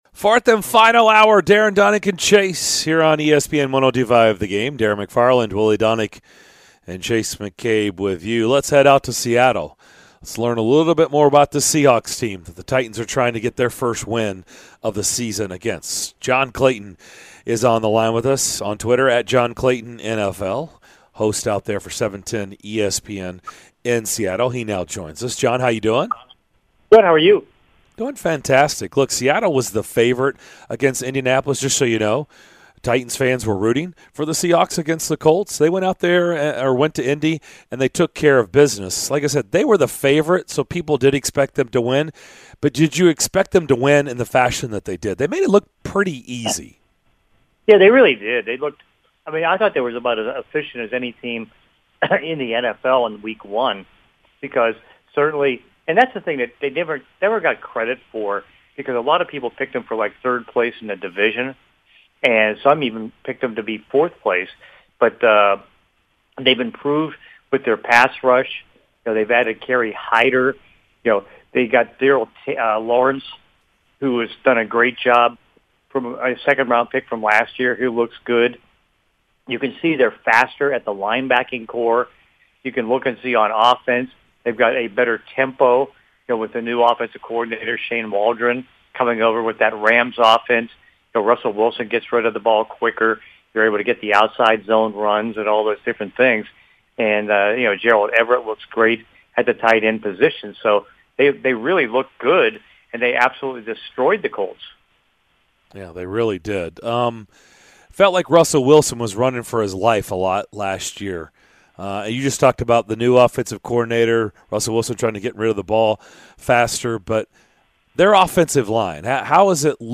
NFL reporter John Clayton joined the DDC to help preview Sunday's game between the Seahawks and Titans!